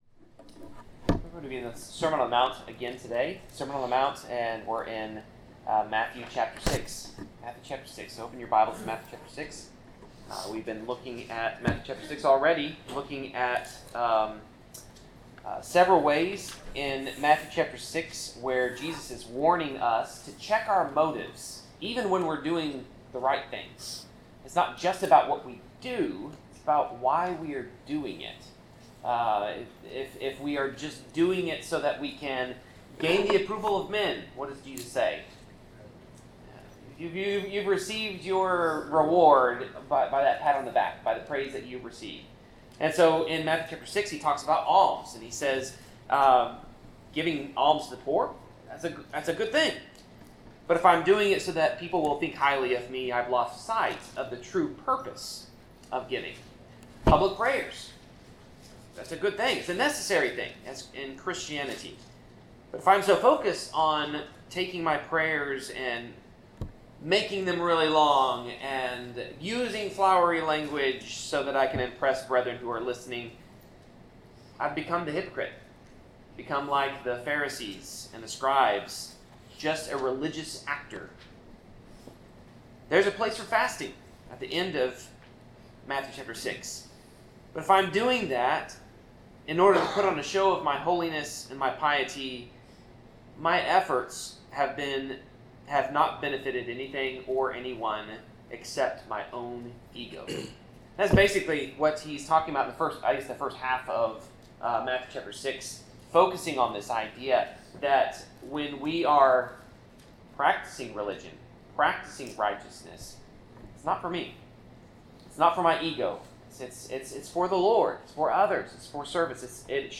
Passage: Matthew 6:9-13, Luke 11:1-13 Service Type: Sermon